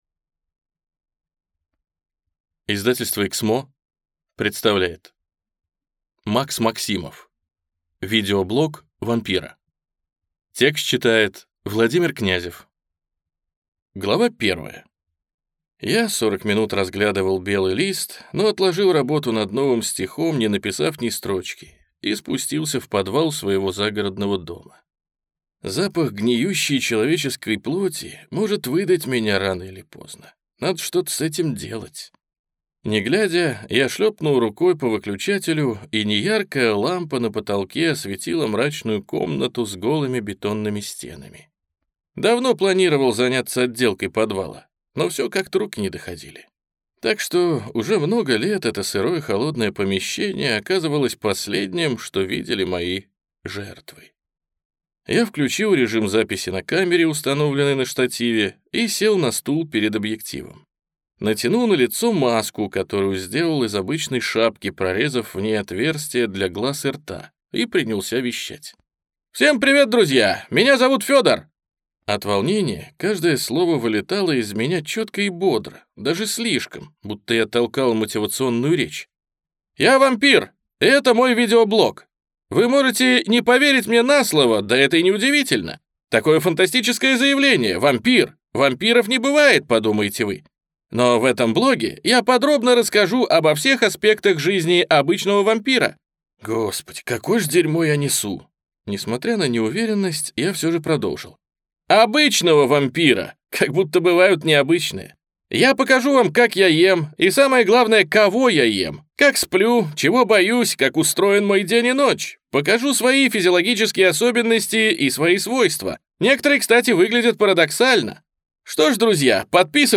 Аудиокнига Видеоблог вампира | Библиотека аудиокниг